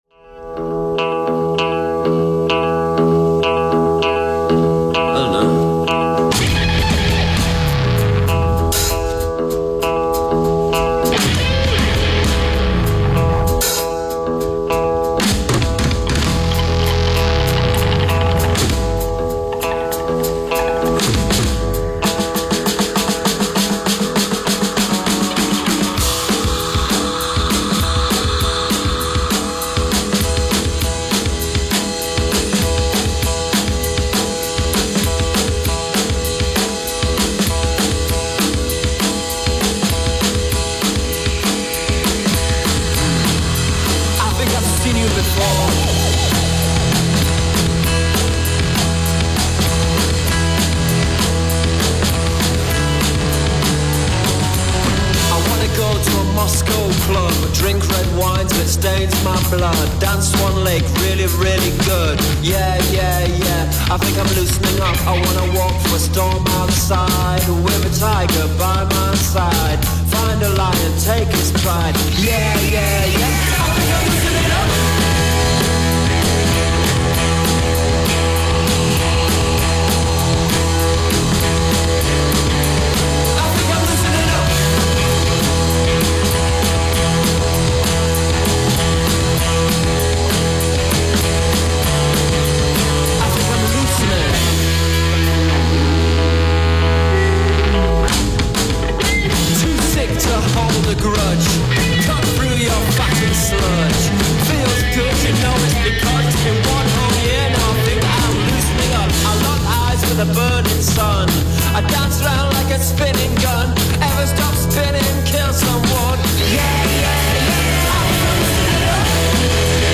recorded live on April 5
garage punk band
guitar
guitar and sax
Drums
bass guitar
keyboard